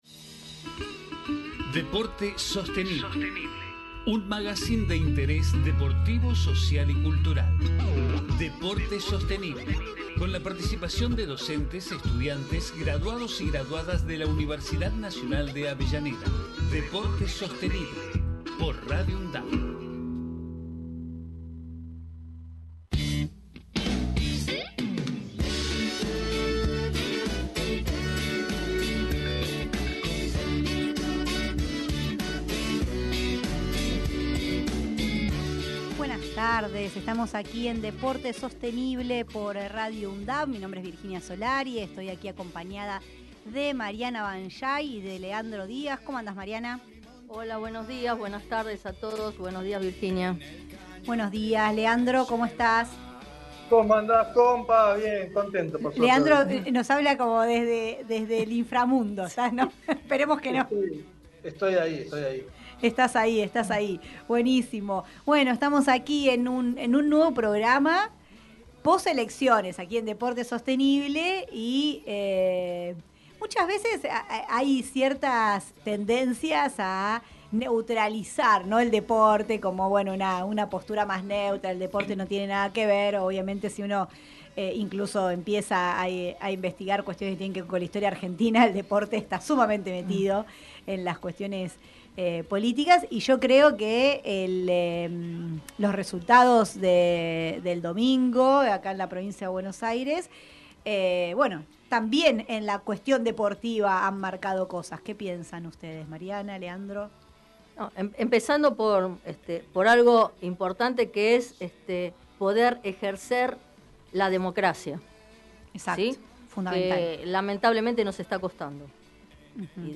Deporte Sostenible Texto de la nota: En cada programa se busca abordar la vinculación estratégica entre gestión deportiva, desarrollo sostenible, salud, cultura, medio ambiente e inclusión social, realizando entrevistas, columnas especiales, investigaciones e intercambio de saberes. Magazine de interés deportivo, social y cultural que se emite desde septiembre de 2012.
Con la participación de docentes, estudiantes y graduados/as de la Universidad Nacional de Avellaneda.